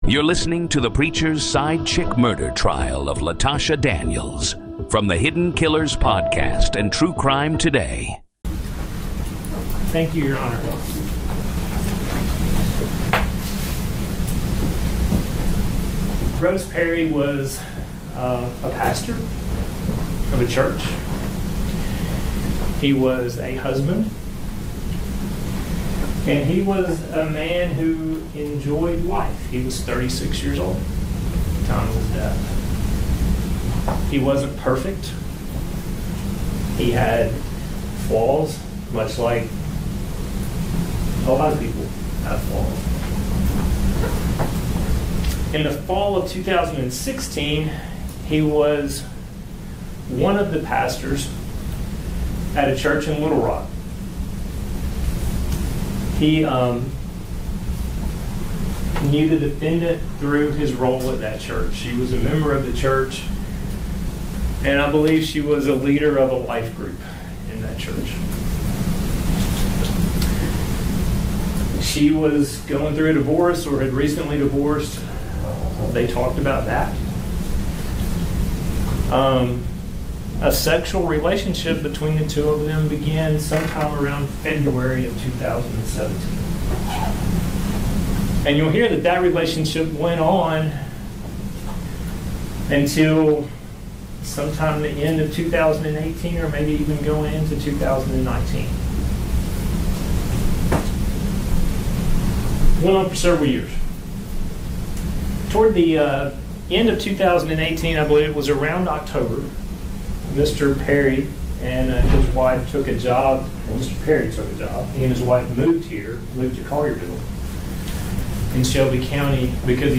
Raw, unedited courtroom coverage